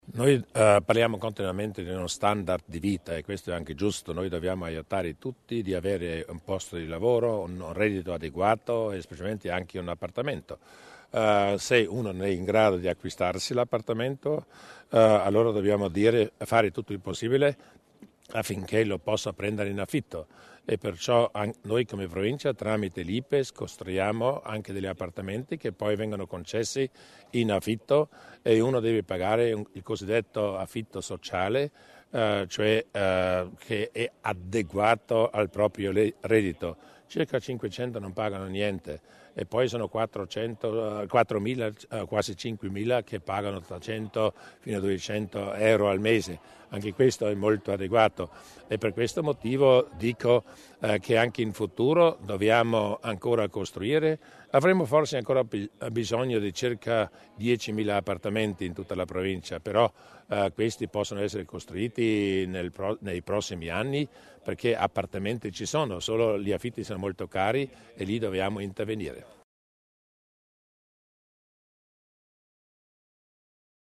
L’Assessore Tommasini sulla politica abitativa provinciale